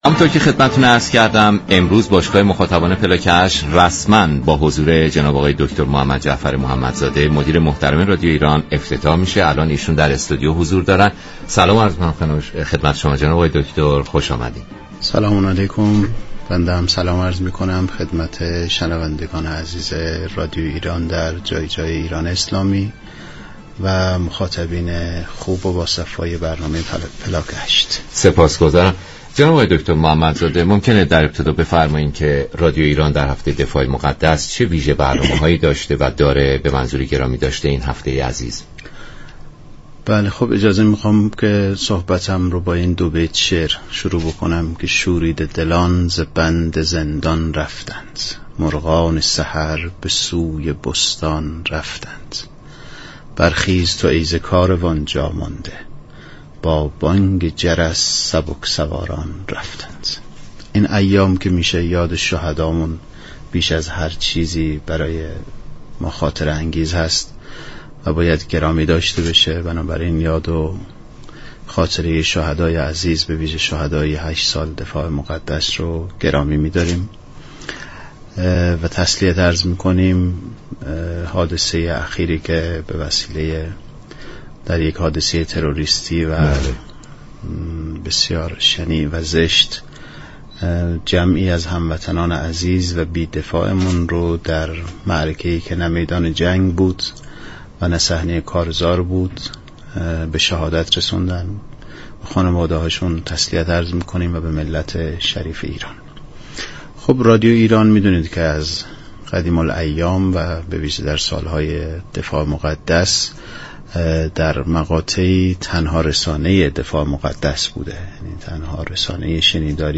در گفت و گو با برنامه پلاك هشت